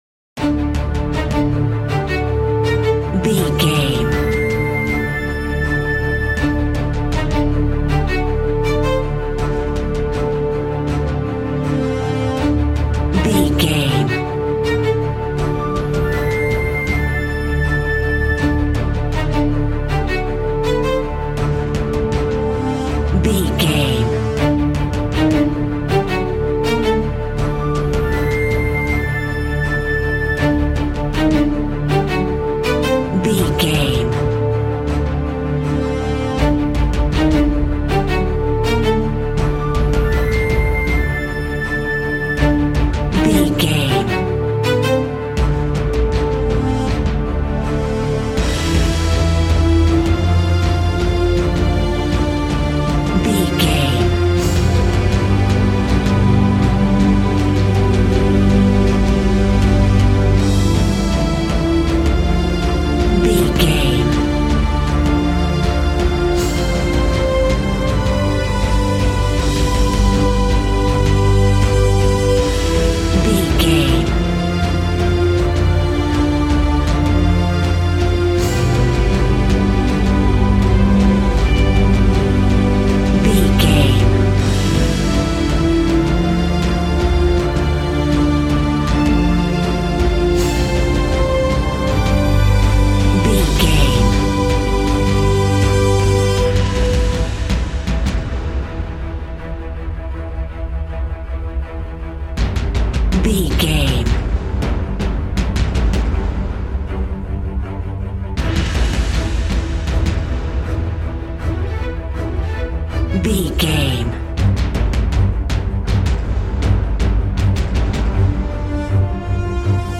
Epic / Action
Fast paced
In-crescendo
Uplifting
Ionian/Major
strings
brass
percussion
synthesiser